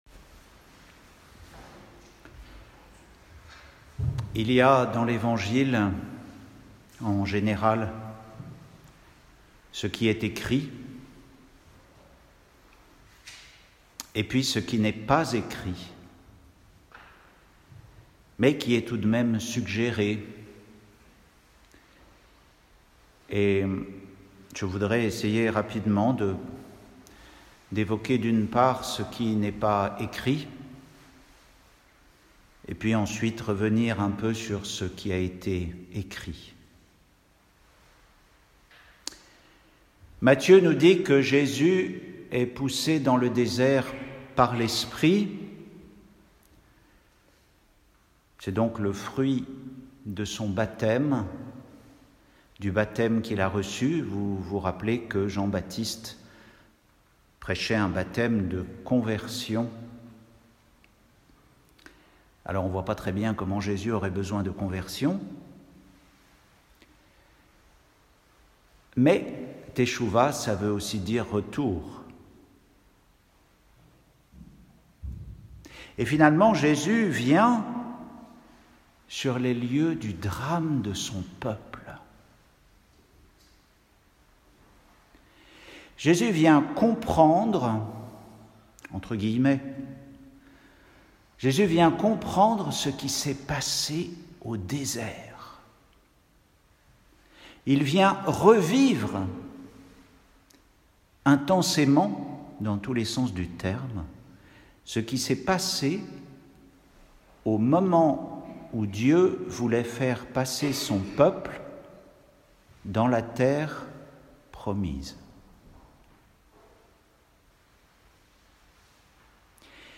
Retrouvez les méditations d’un moine sur les lectures de la messe du jour.
Homélie pour le 1er dimanche du Carême